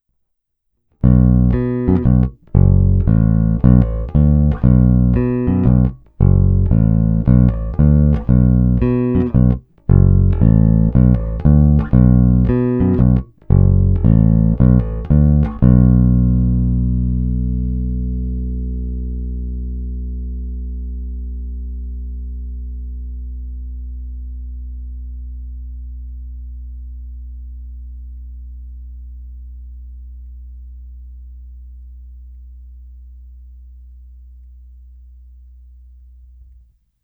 Zvuk je tlustý, zvonivý vrčák s okamžitým nástupem pevného tónu.
Není-li uvedeno jinak, následující nahrávky jsou provedeny rovnou do zvukovky a dále kromě normalizace ponechány bez úprav.
Tónová clona vždy plně otevřená.
Hra nad snímačem